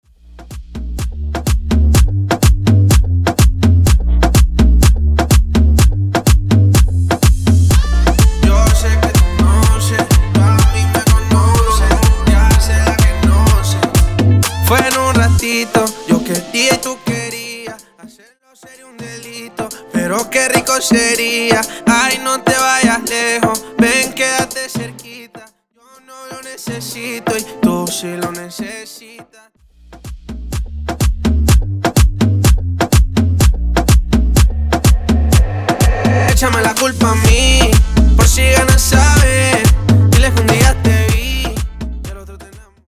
Intro Dirty, Coro Dirty